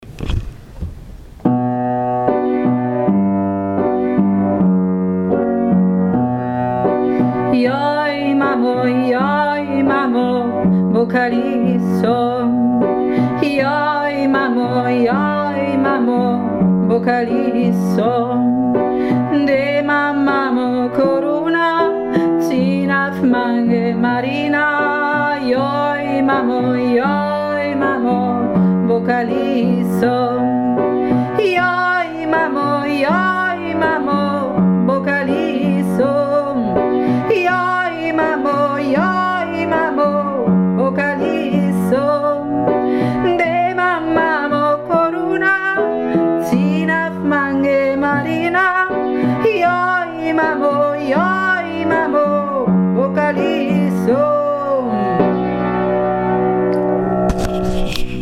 Joy Mamo - Klagelied der Roma